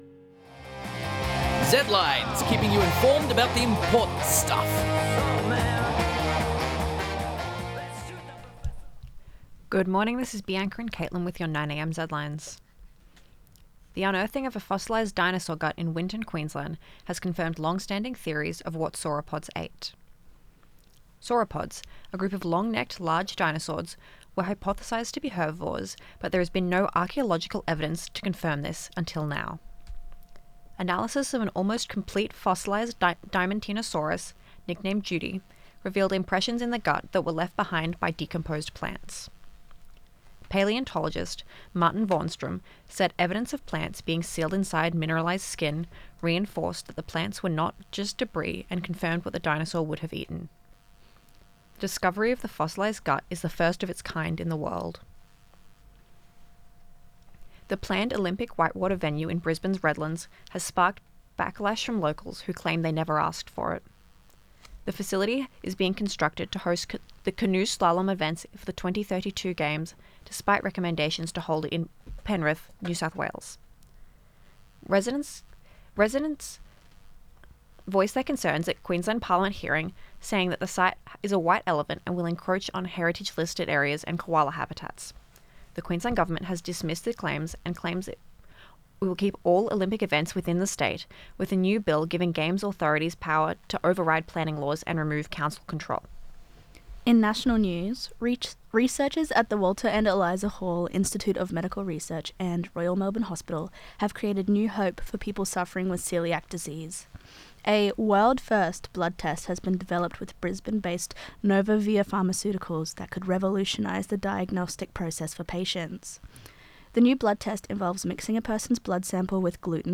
eGuide Travel via Flickr Zedlines Bulletin Zedline 9am 10.06.2025.mp3